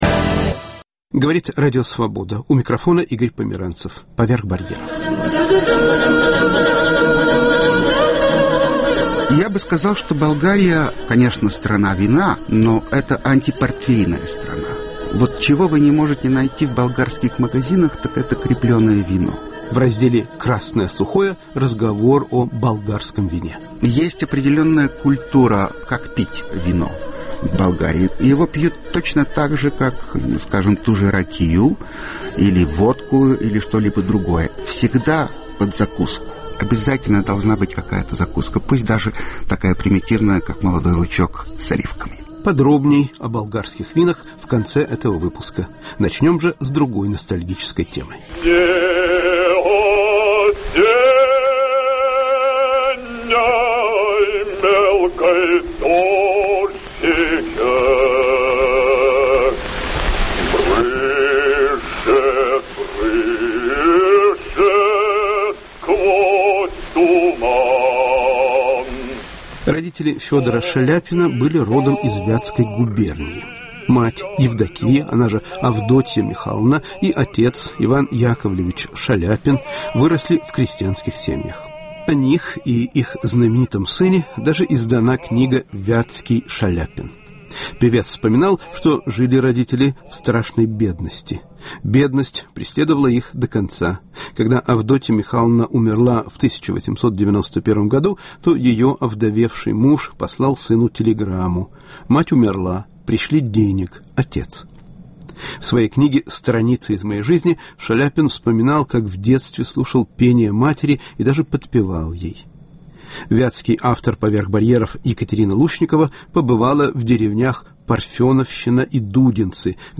"Деревня Шаляпиных" Репортаж из Кировской области